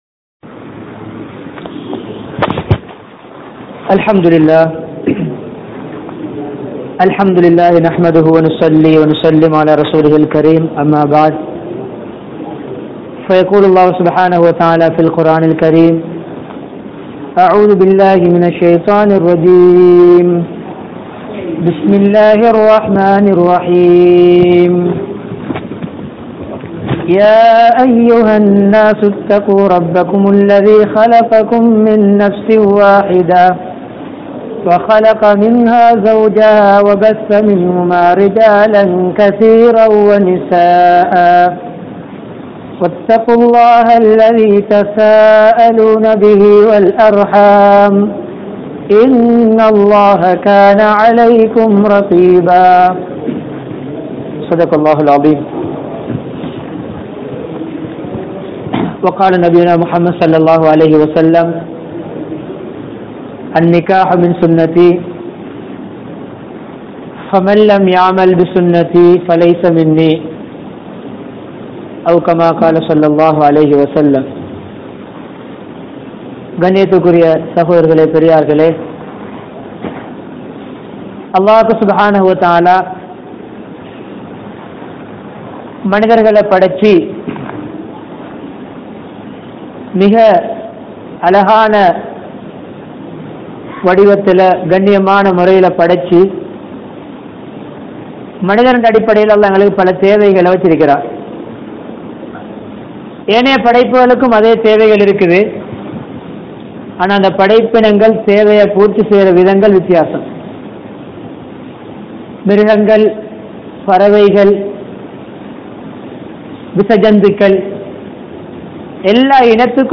Manaivi Vaalkaien Oru Pahuthi (மனைவி வாழ்க்கையின் ஒரு பகுதி) | Audio Bayans | All Ceylon Muslim Youth Community | Addalaichenai
Elamalpotha, Majmaulkareeb Jumuah Masjith